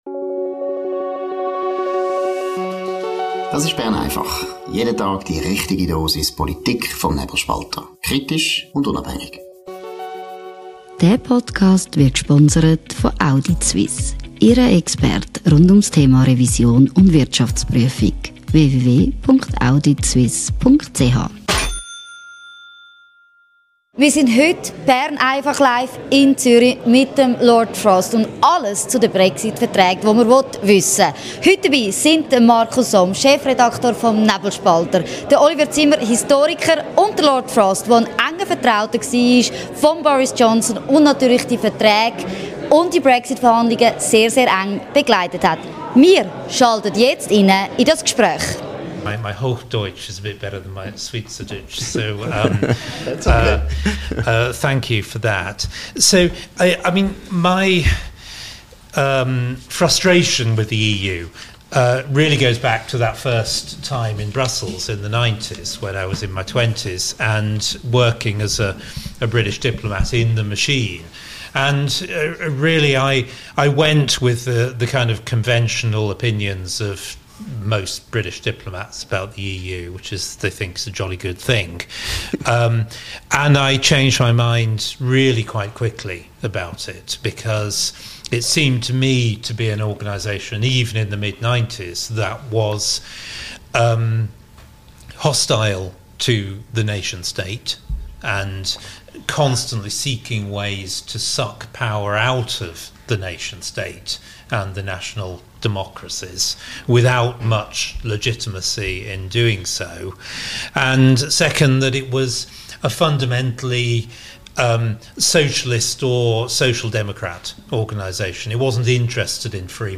Aufgezeichnet am 23. Oktober 2025 im Zunfthaus zur Haue in Zürich.